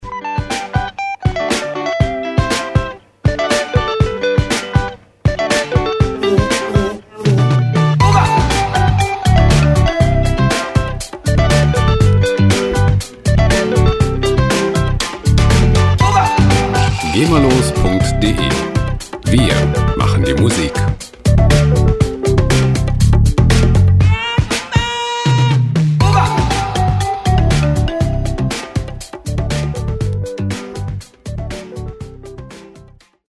free World Music Loops
Musikstil: African Pop
Tempo: 120 bpm